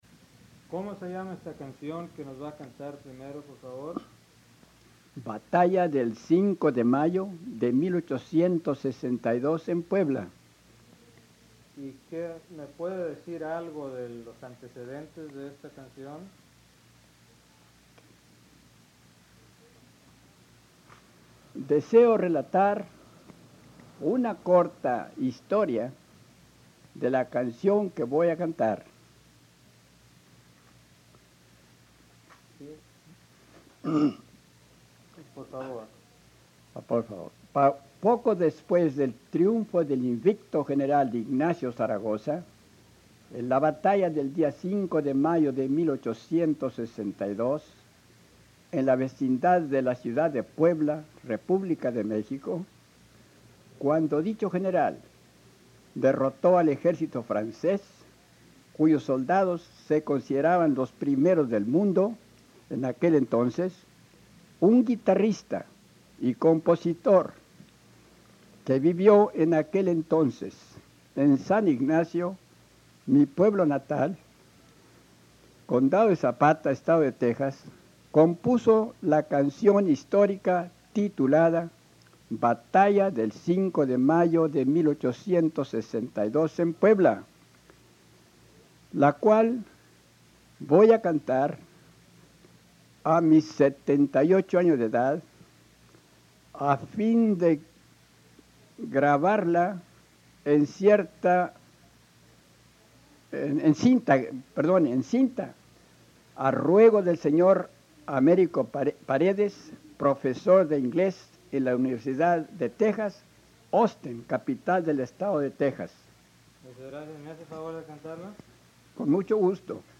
In this post, I dive deeper into the genre of corridos (narrative ballads) as they are represented in Parédes’s collection.
The practice of singing historical corridors appears to be alive and well in 1950s south Texas, which is when and where Paredes conducted the bulk of his field recordings.